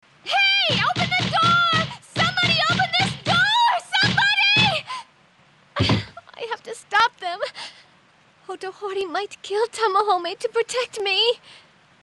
Miaka's English dub is one of the most awful things to listen to, ever.
Miaka's name is pronounced "Mi-ya-ka" although in the English dub it's often reduced to "Mi-yah-ka".
miaka_english.mp3